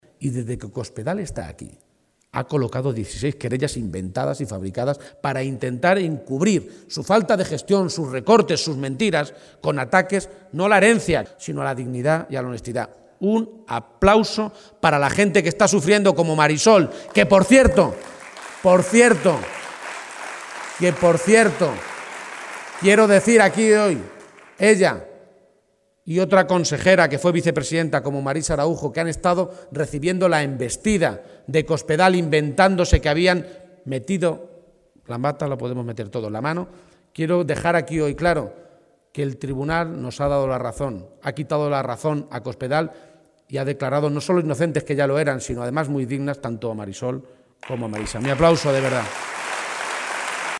“No podemos tolerar que eso quede políticamente impune”, ha afirmado durante un acto de campaña en la localidad de Marchamalo (Guadalajara), en la que ha sido necesario cambiar de ubicación ante la masiva afluencia de militantes y simpatizantes socialistas.